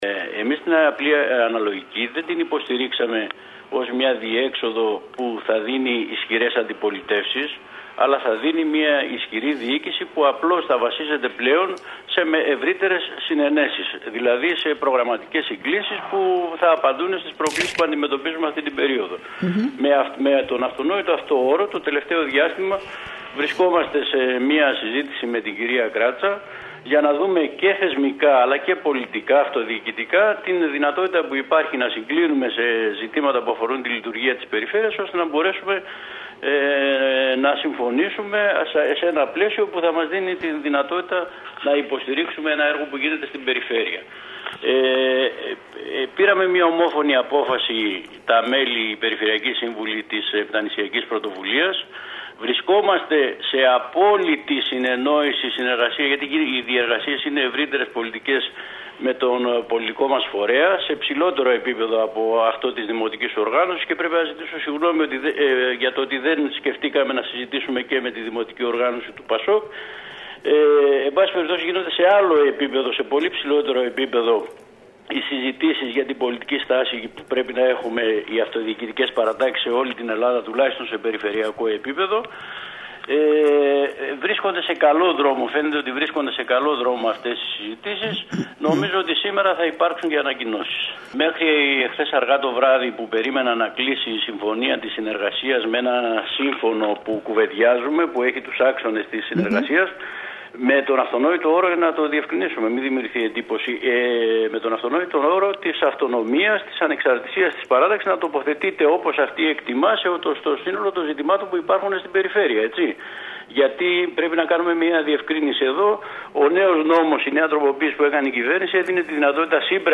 Μιλώντας στην ΕΡΤ Κέρκυρας ο περιφερειακός σύμβουλος της Επτανησιακής Πρωτοβουλίας Παναγιώτης Ποζίδης αναφορικά με την συνεργασία της παράταξής του με την παράταξη Κράτσα, υπογράμμισε πως η κεντρική γραμμή του  κόμματος κάνει λόγο για συνεργασίες στους οργανισμούς τοπικής αυτοδιοίκησης και επεσήμανε ότι επρόκειτο για ομόφωνη απόφαση των περιφερειακών συμβούλων που όμως θέτουν συγκεκριμένους όρους για την αυτονομία της Πρωτοβουλίας.